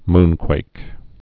(mnkwāk)